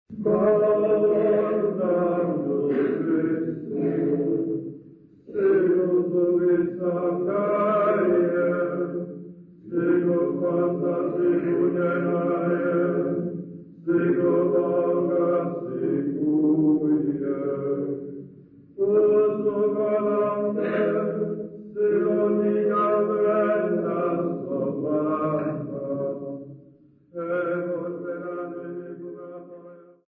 Praise poet
Folk music
Sacred music
Field recordings
Xhosa festival workshop performance unaccompanied
7.5 inch reel
96000Hz 24Bit Stereo